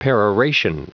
Prononciation du mot peroration en anglais (fichier audio)
Prononciation du mot : peroration